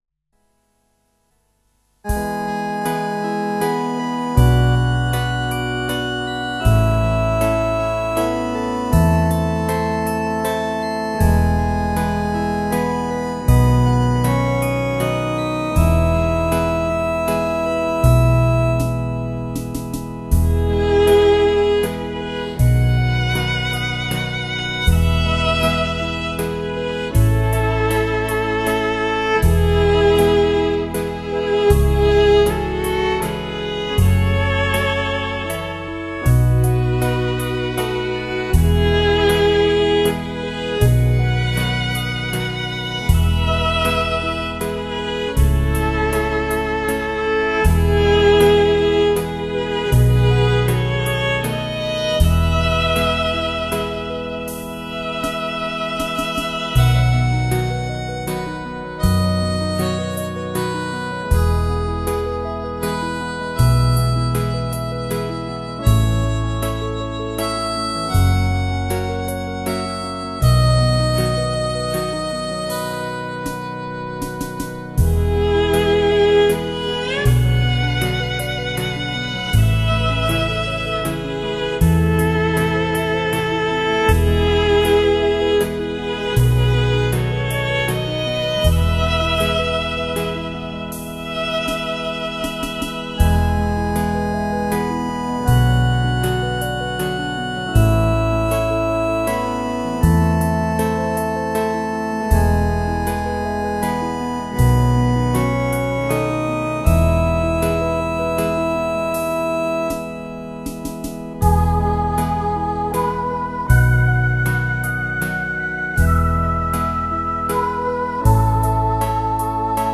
试听为低品质wma，下载为320k/mp3